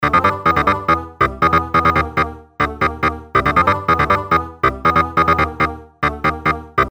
技术合成器
描述：一个技术性的合成器
Tag: 140 bpm Techno Loops Synth Loops 1.15 MB wav Key : Unknown